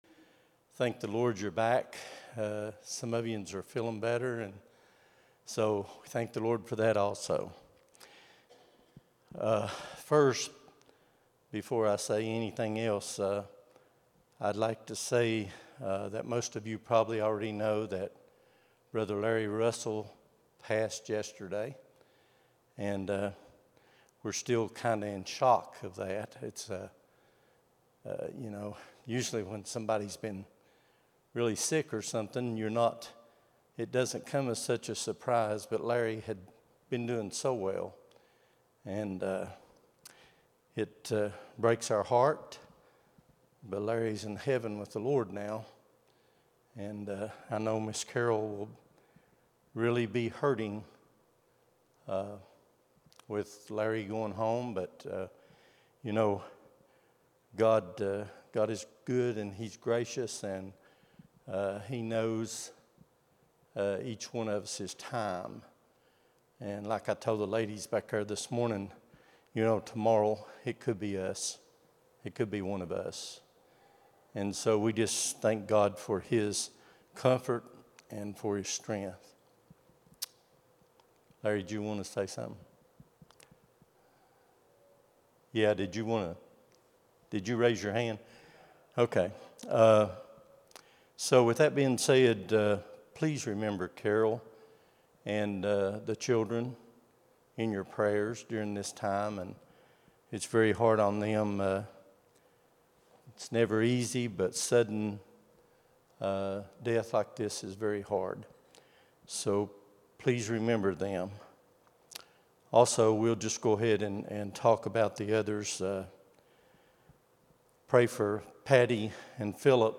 Sunday School lesson at Buffalo Ridge Baptist Church in Gray, Tn.